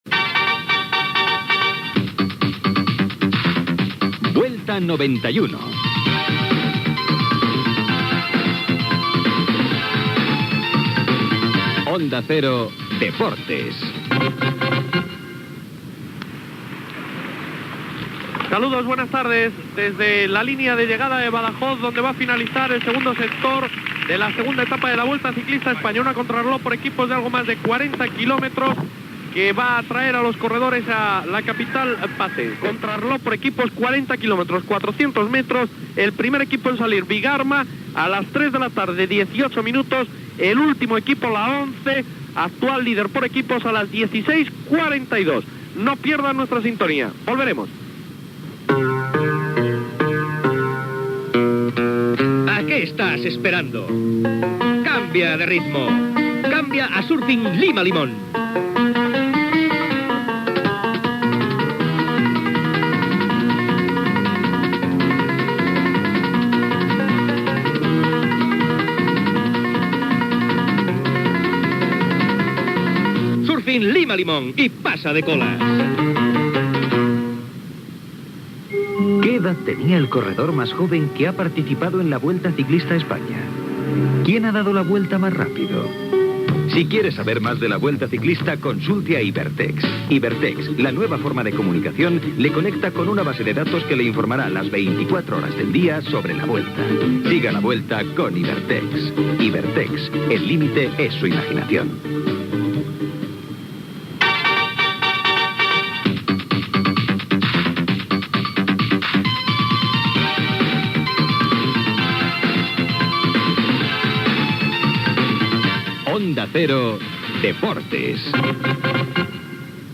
Careta de la transmissió, connexió amb la segona etapa de la Vuelta a España que se celebra a Badajoz, publicitat, indicatiu
Esportiu